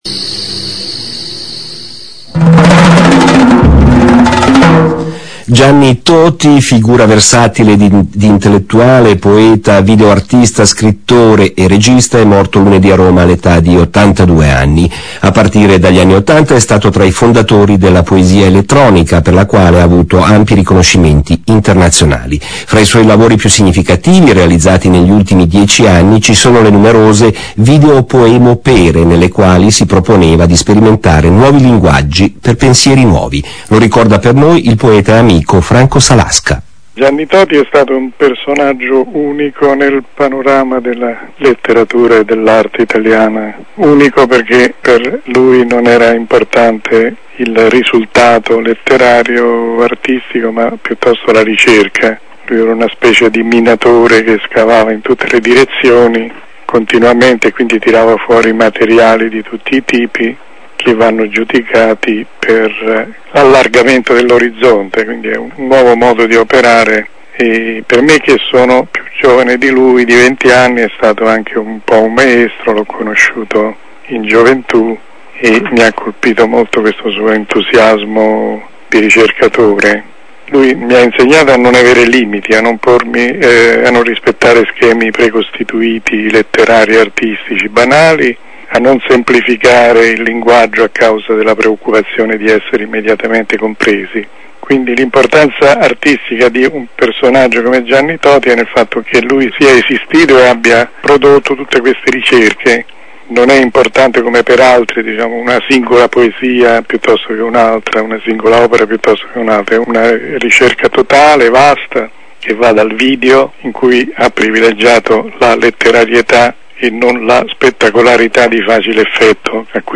Lunedì 8 gennaio 2007, è venuto a mancare Gianni TOTI - Il mio ritratto di Gianni, trasmesso da Radio Svizzera Italiana, 10 gennaio 2007 ore 8, da scaricare in